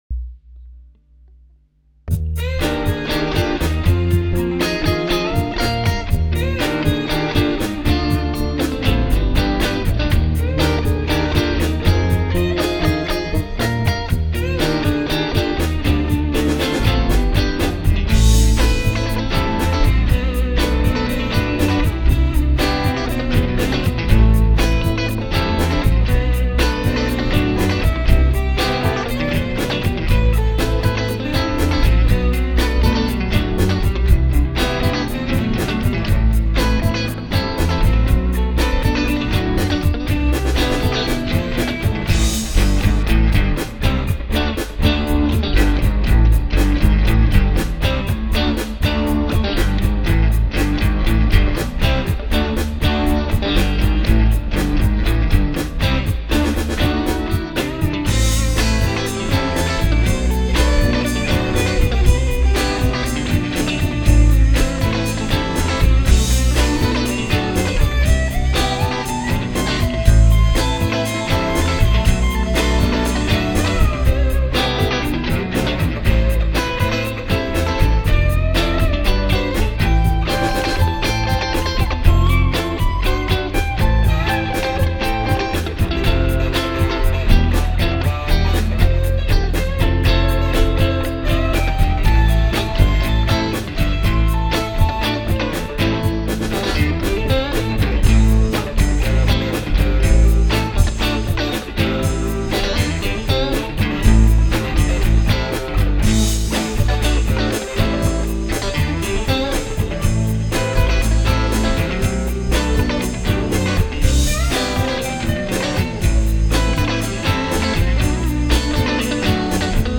ça y'est (mais y'a pas de paroles)